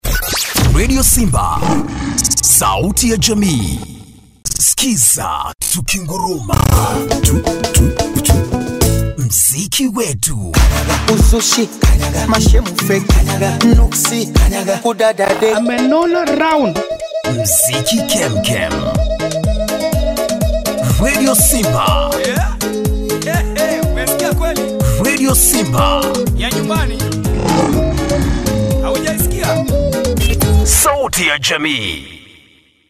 English (African)
Radio Imaging
- A professionally built vocal booth with Broadcast quality